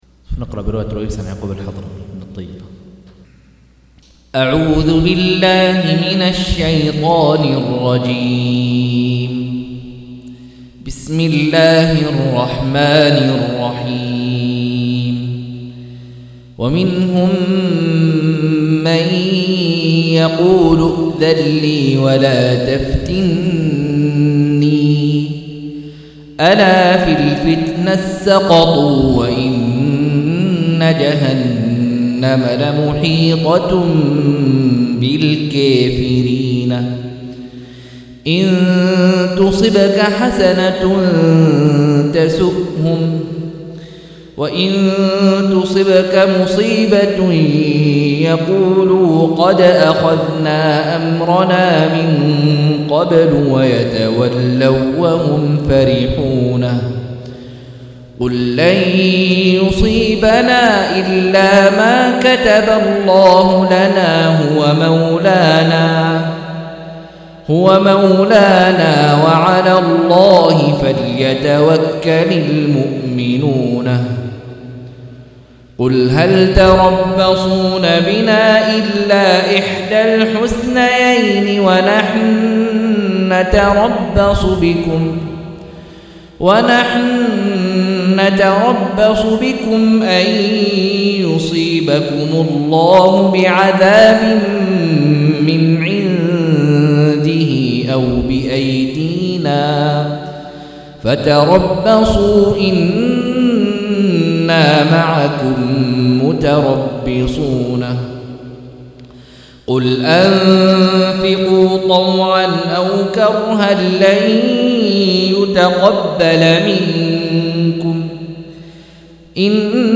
185- عمدة التفسير عن الحافظ ابن كثير رحمه الله للعلامة أحمد شاكر رحمه الله – قراءة وتعليق –